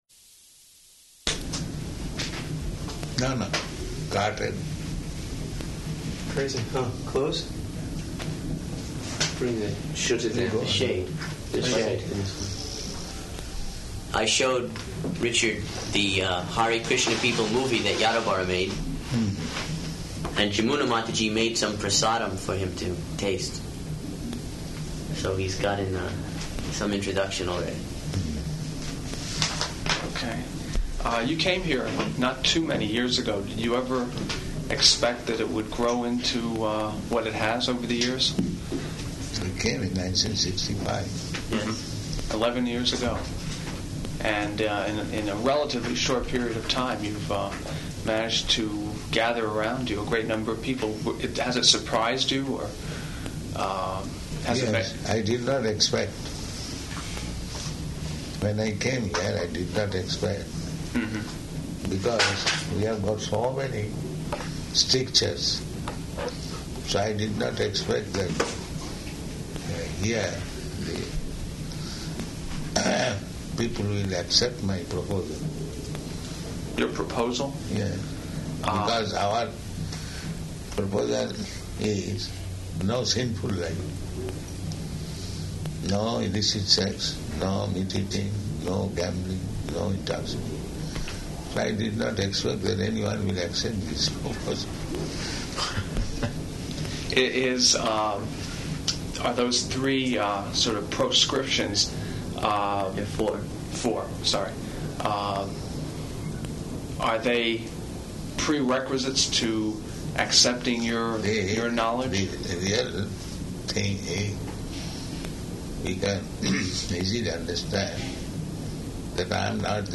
-- Type: Lectures and Addresses Dated: June 10th 1976 Location: Los Angeles Audio file